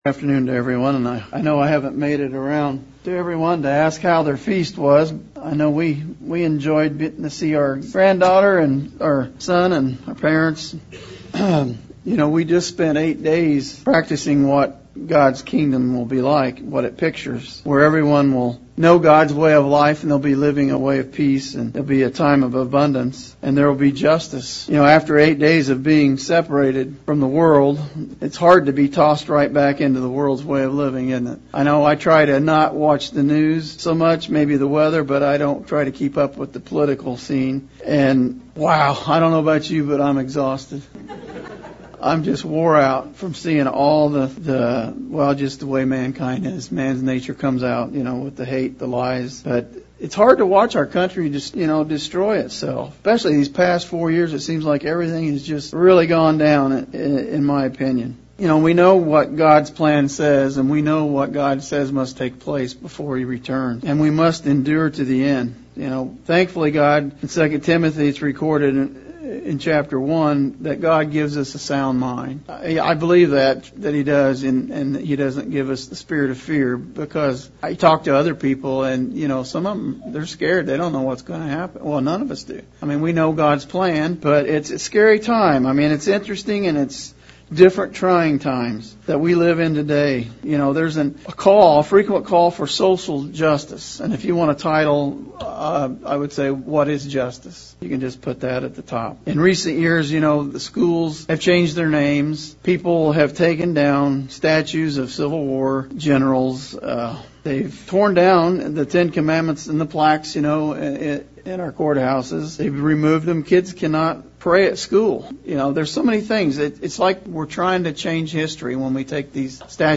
Sermon looking at the subject of Justice and Righteousness. Is what we see today Justice, or does God have something better in mind?